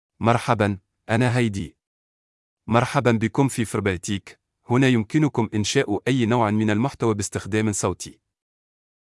Hedi — Male Arabic (Tunisia) AI Voice | TTS, Voice Cloning & Video | Verbatik AI
Voice sample
Listen to Hedi's male Arabic voice.
Male
Hedi delivers clear pronunciation with authentic Tunisia Arabic intonation, making your content sound professionally produced.